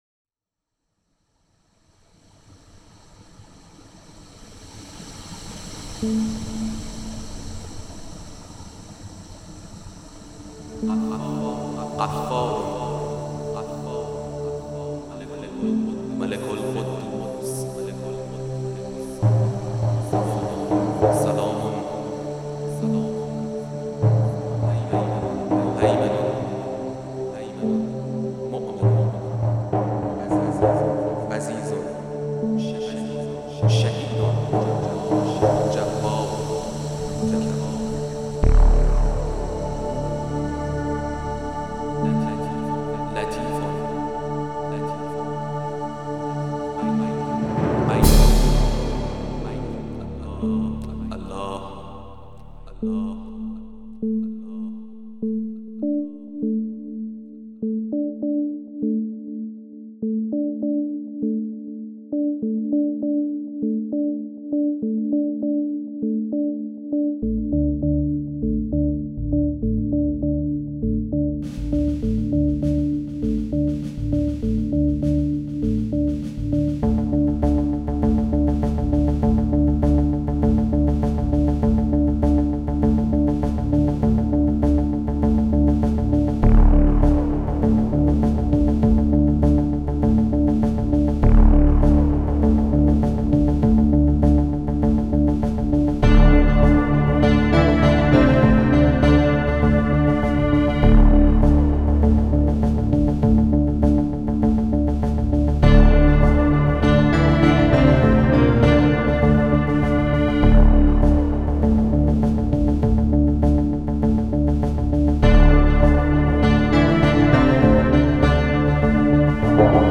موسیقی بی کلام الکترونیک